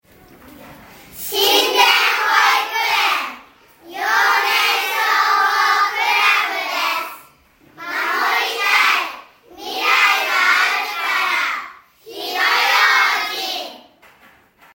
「火災予防運動」や「歳末警戒」の一環で、管内の子ども達（幼年消防クラブ員）が録音した音声を消防車から流し、パトロールをします。
子ども達は、かわいい元気いっぱいの声で「守りたい 未来があるから 火の用心」と音声を録音して協力して頂きました。
広報音声（順不同）